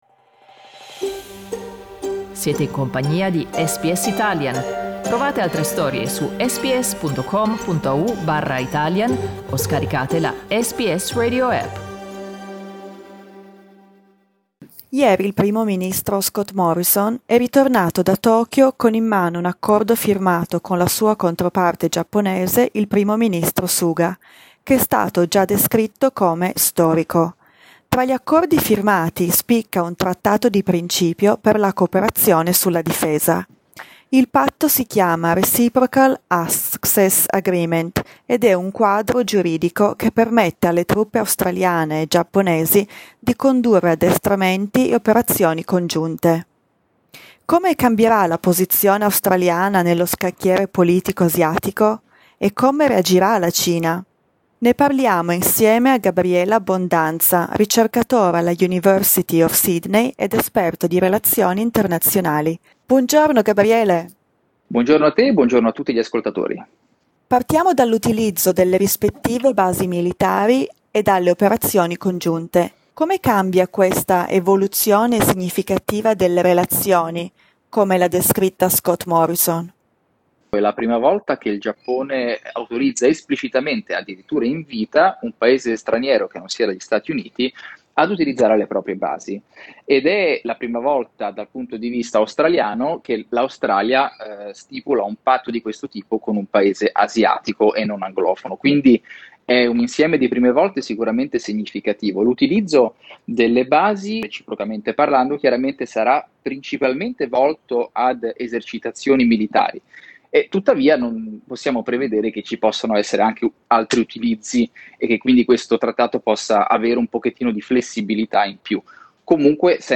Ascolta l'intervista: LISTEN TO Australia e Giappone verso la firma di un trattato sulla difesa SBS Italian 06:45 Italian Le persone in Australia devono stare ad almeno 1,5 metri di distanza dagli altri.